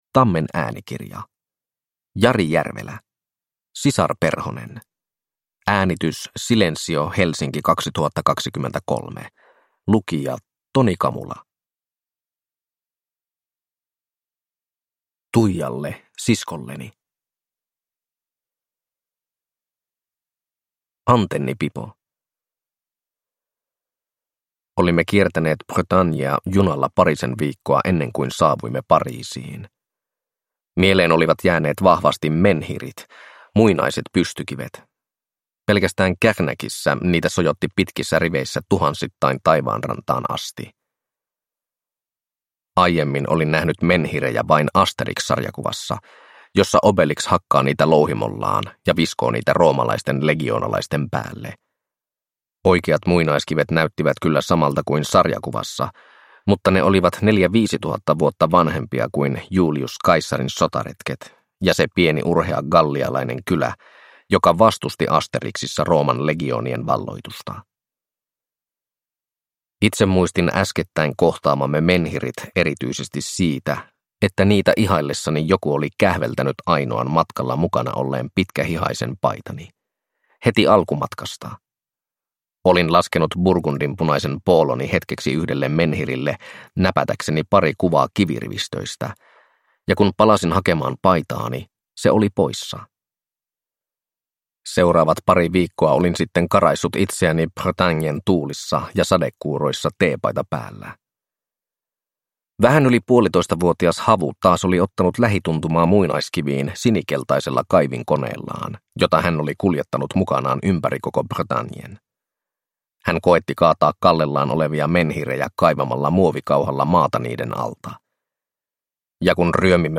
Sisarperhonen ja muita matkatarinoita – Ljudbok – Laddas ner